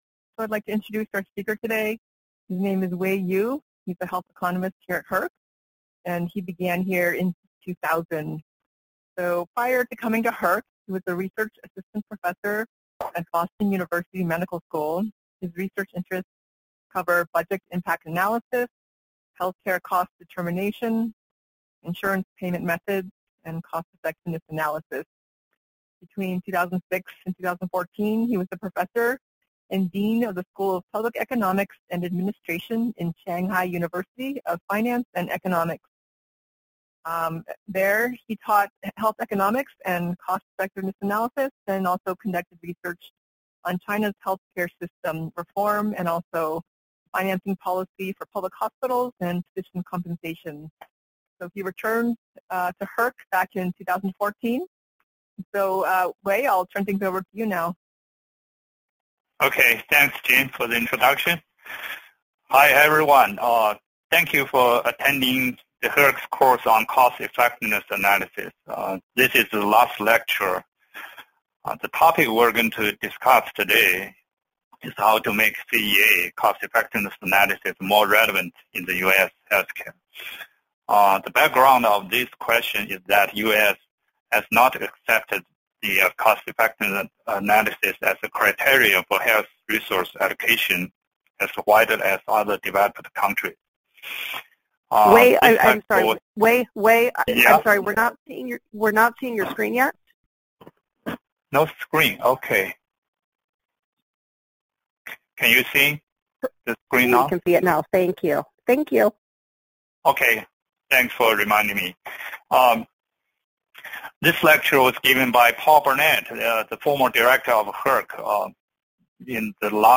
HERC Cost Effectiveness Analysis Seminar